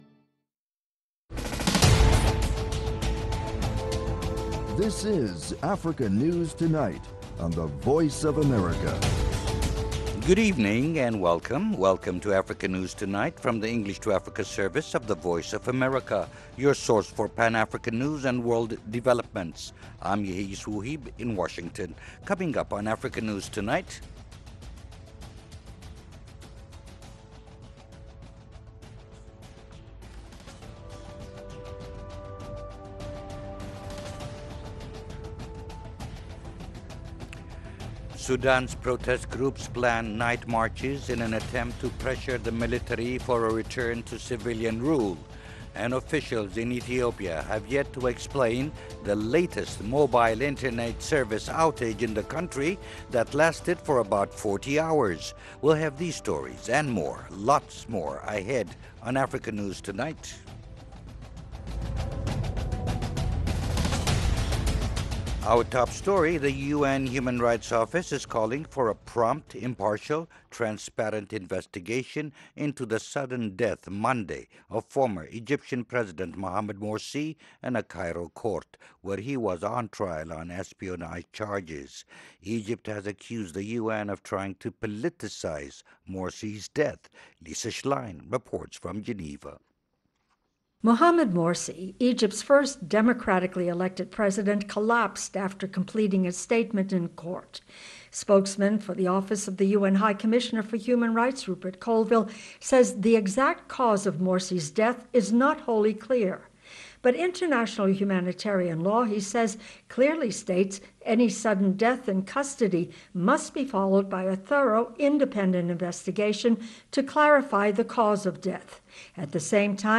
Africa News Tonight is a 30-minute news magazine program that airs twice each evening Monday through Friday, at 1600 and 1800 UTC/GMT.